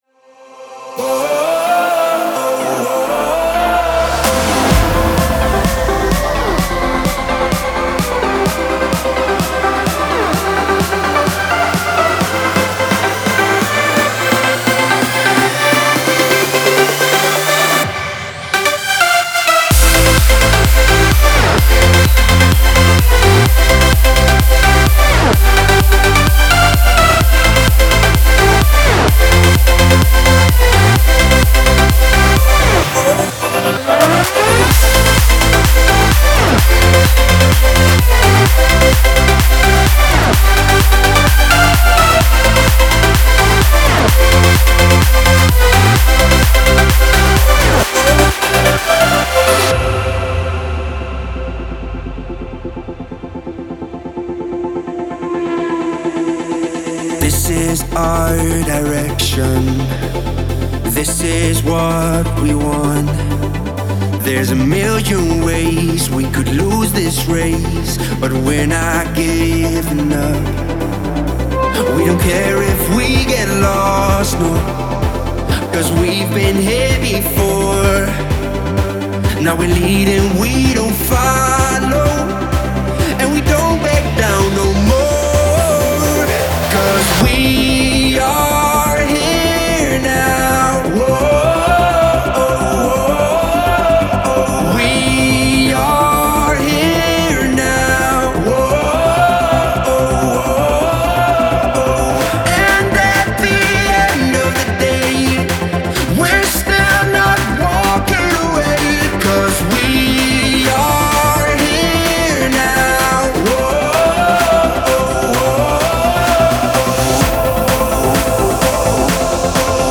это энергичная трек в жанре EDM